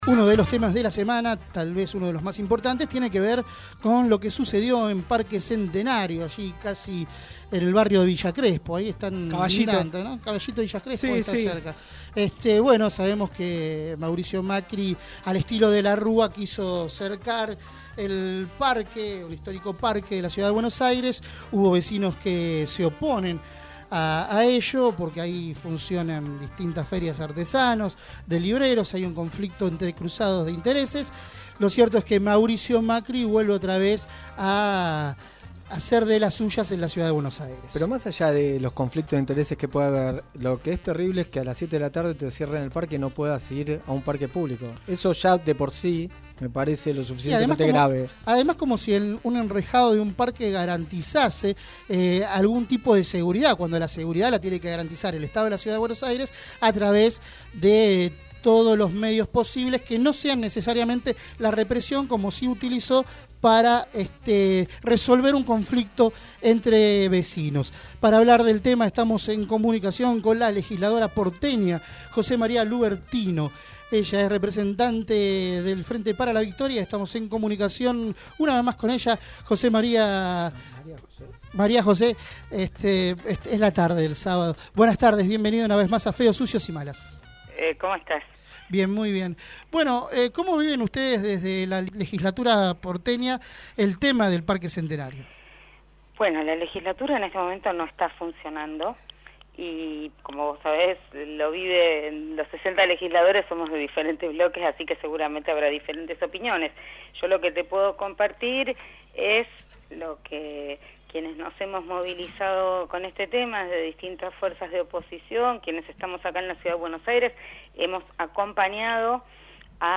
María José Lubertino, diputada porteña del Frente Para la Victoria, en diálogo